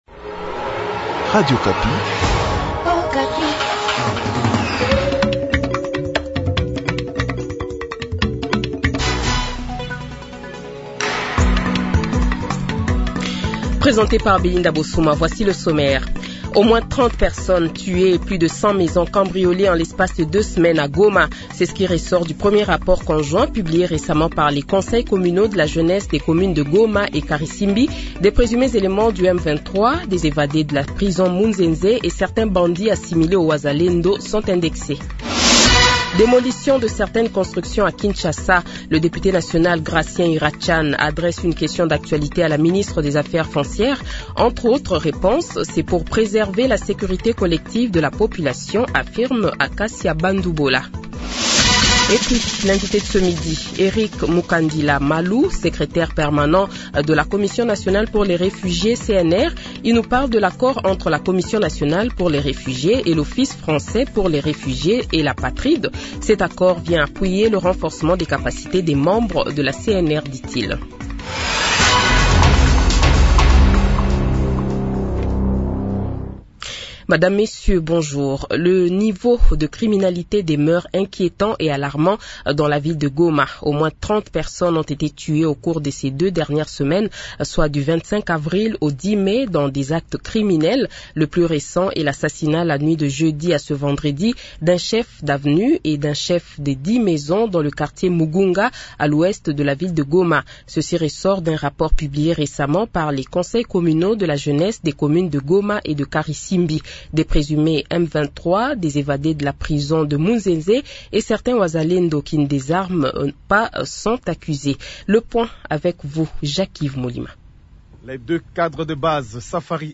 Le Journal de 12h, 16 Mai 2025 :